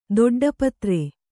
♪ doḍḍa patre